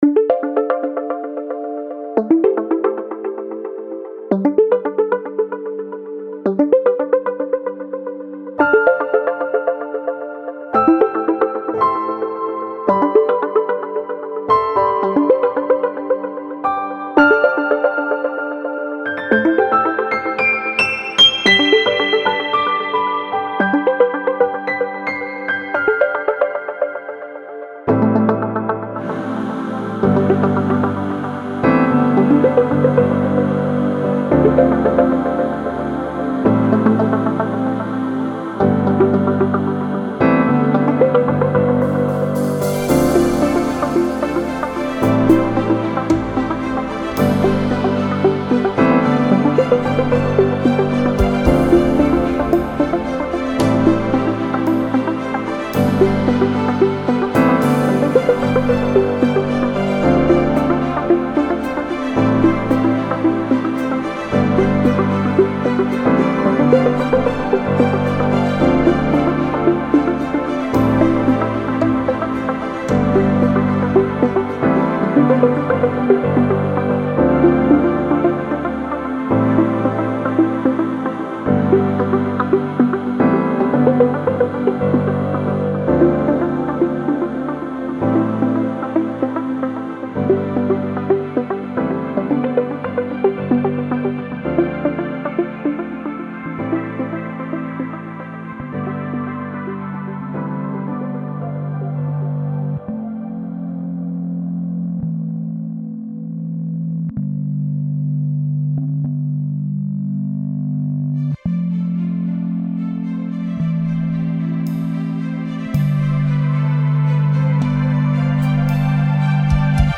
Now this is straight up commercial music, like something for the TV.
Especially digging the lovely use of synths here, this was just really nice.